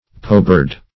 poebird - definition of poebird - synonyms, pronunciation, spelling from Free Dictionary Search Result for " poebird" : The Collaborative International Dictionary of English v.0.48: Poebird \Po"e*bird`\, n. (Zool.)